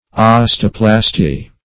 Search Result for " osteoplasty" : The Collaborative International Dictionary of English v.0.48: Osteoplasty \Os"te*o*plas`ty\, n. [Osteo- + -plasty.]